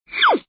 丢屎.mp3